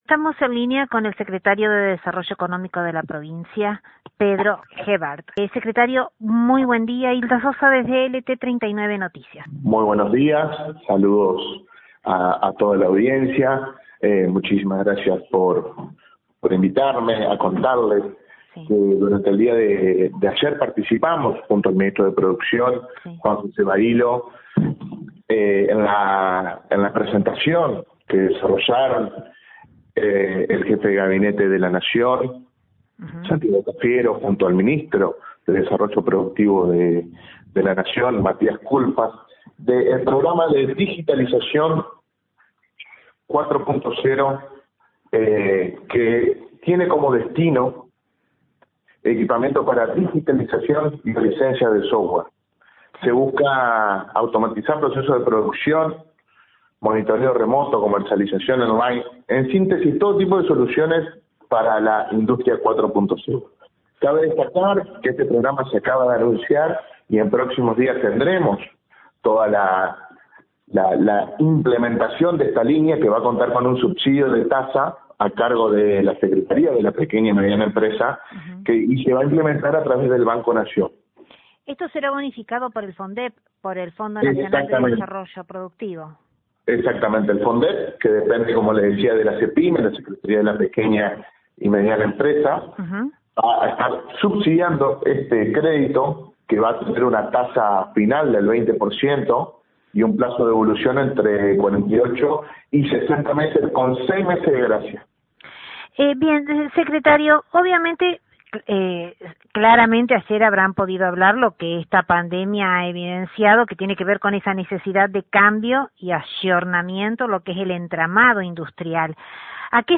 Nuestro medio, dialogó con el Secretario de Desarrollo Económico de dicha cartera, Pedro Gebhart; quien en primera instancia, hizo un detalle del mencionado programa, el cual será bonificado por el Fondep (Fondo Nacional de Desarrollo Productivo).